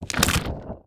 SPLAT_Crunch_Crack_04_mono.wav